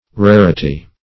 Rarity \Rar"i*ty\ (r[a^]r"[i^]*t[y^]; 277), n.; pl. Rarities